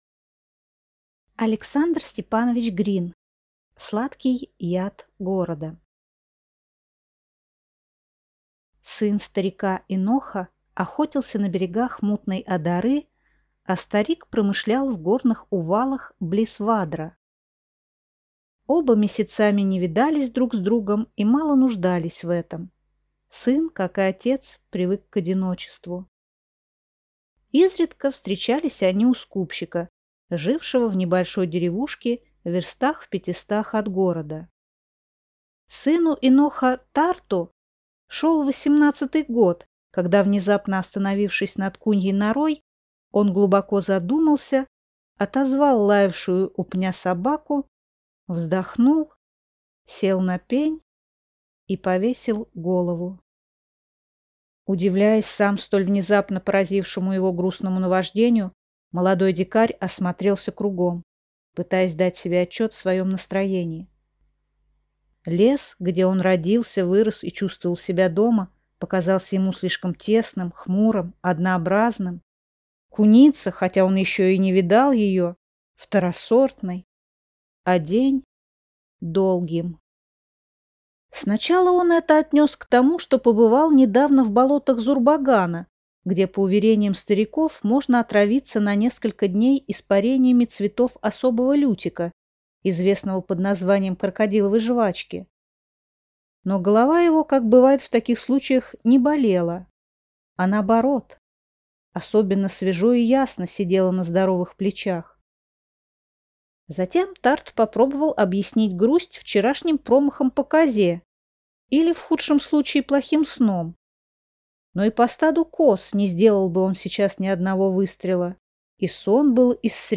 Аудиокнига Сладкий яд города | Библиотека аудиокниг